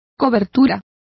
Complete with pronunciation of the translation of coverings.